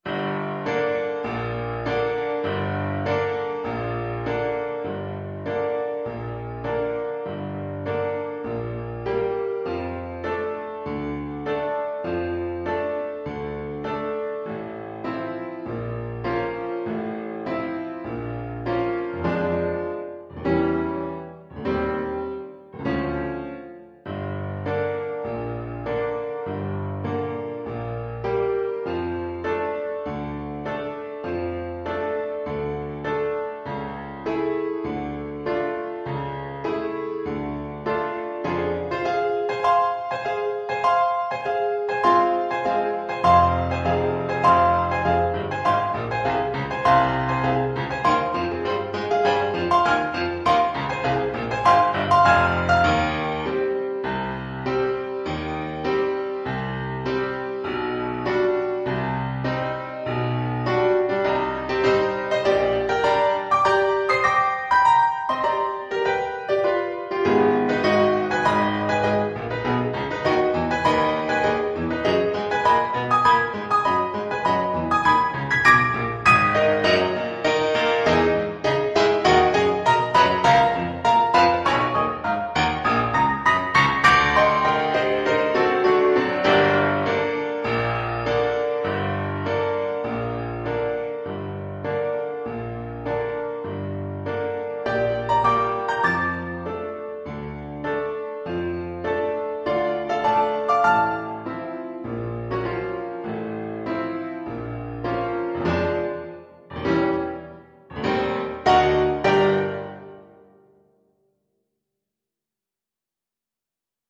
Classical (View more Classical Cello Music)